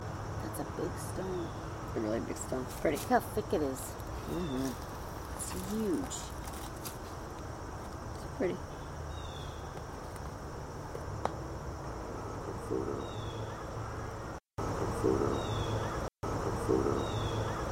Rose Hill 5 EVP #4 -  First you hear the ladies commenting on the stone.  Then a deep male voice comes out crystal clear and says "It for real."